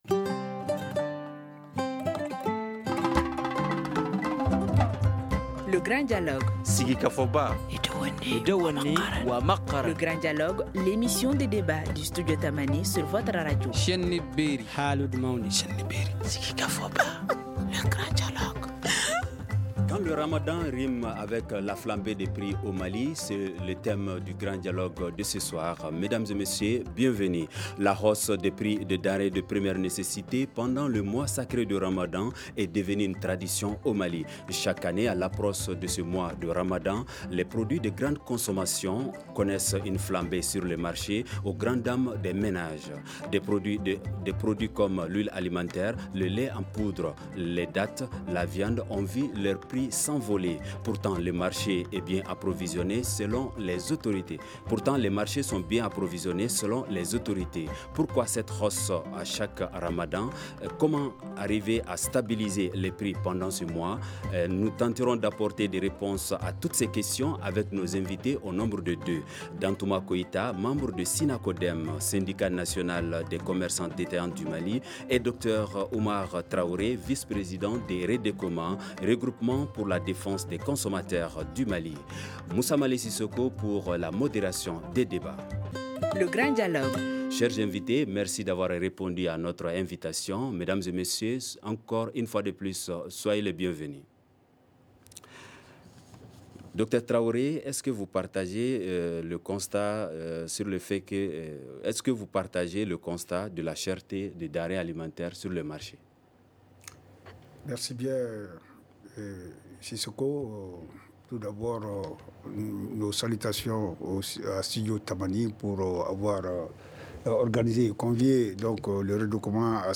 Nous tenterons d’apporter des réponses à toutes ces questions avec nos invités :